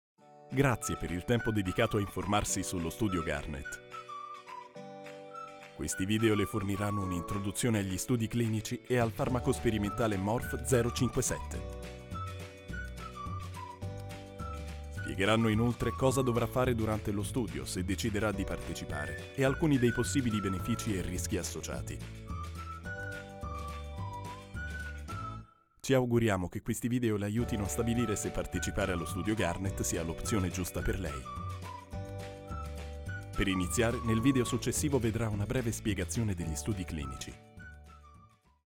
Jonge stemacteur met ervaring in dubbing.
E-learning